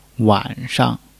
wan3-shang.mp3